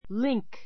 link líŋk り ン ク 名詞 ❶ 結び付ける人[物], きずな; つながり, 結び付き ❷ （鎖 くさり の） 輪 A chain is only as strong as its weakest link.